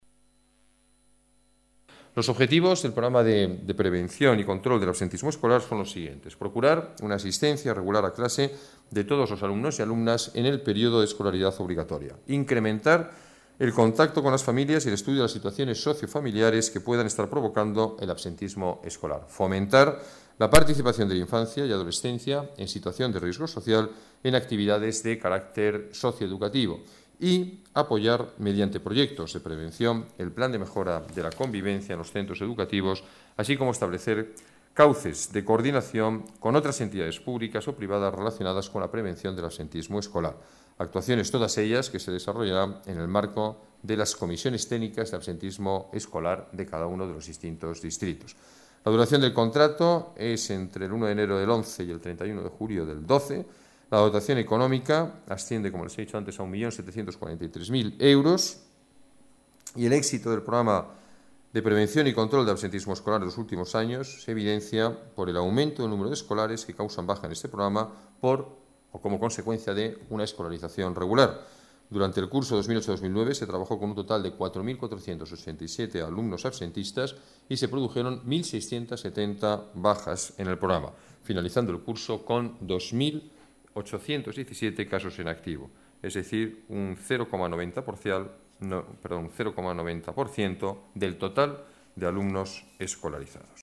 Nueva ventana:El alcalde, Alberto Ruiz-Gallardón, habla de la lucha del Ayuntamiento contra el absentismo escolar